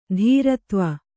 ♪ dhīratva